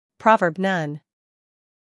英音/ ˈprɒvɜːb / 美音/ ˈprɑːvɜːrb /